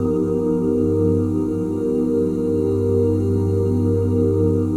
OOHGSHARP9.wav